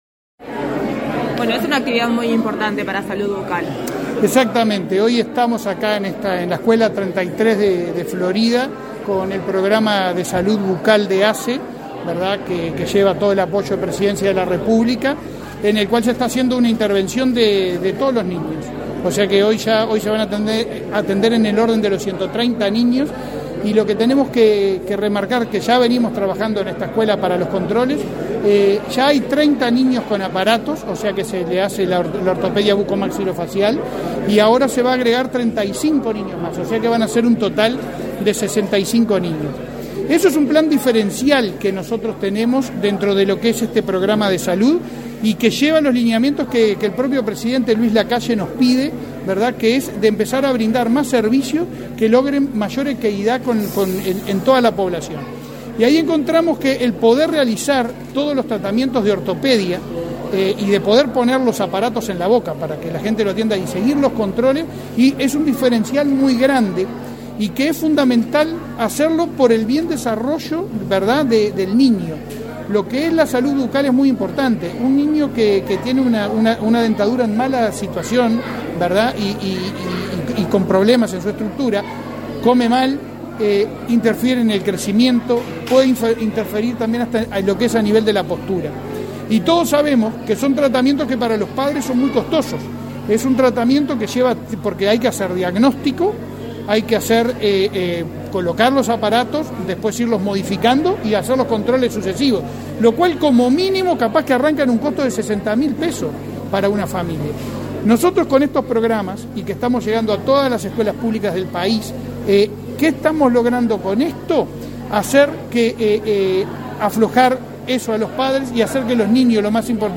Entrevista al presidente de la Administración de los Servicios de Salud del Estado (ASSE), Leonardo Cipriani
Entrevista al presidente de la Administración de los Servicios de Salud del Estado (ASSE), Leonardo Cipriani 07/06/2022 Compartir Facebook X Copiar enlace WhatsApp LinkedIn Tras participar en la intervención del Plan Nacional de Salud Bucal en la escuela n.°33, en la ciudad de Florida, este 7 de junio, el presidente de ASSE efectuó declaraciones a Comunicación Presidencial.